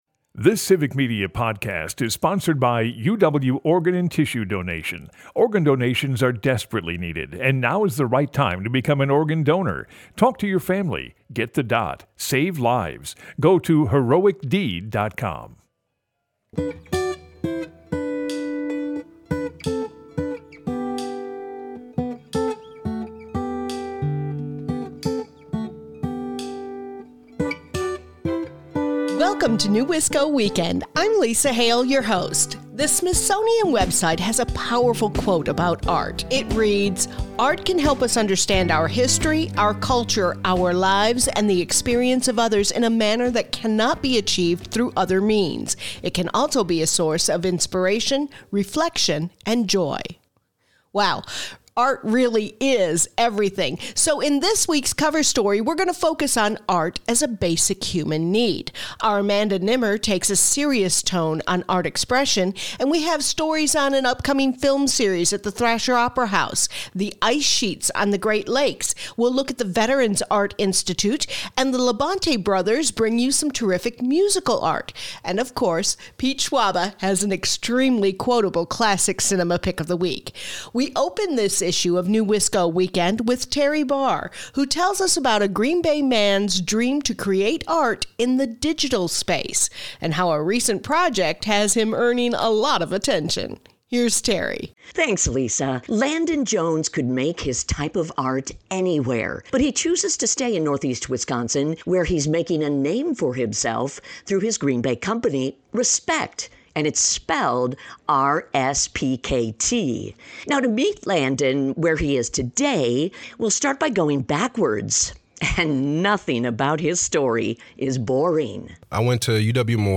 NEWisco Weekend is a news magazine-style show filled with conversations and stories about issues, entertainment, and culture making the Fox Valley, Green Bay and beyond -- a rich, unique area to call home.